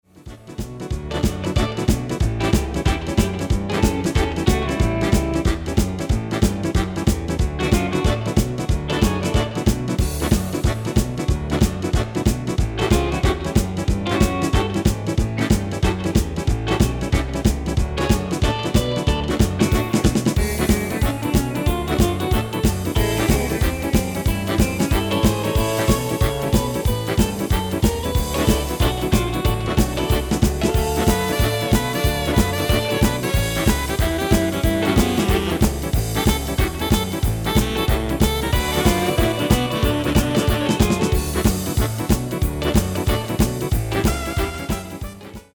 MP3 backing track arranged in the style of:
Genre: Country & Western
Key: G
File type: 44.1KHz, 16bit, Stereo
Demo's played are recordings from our digital arrangements.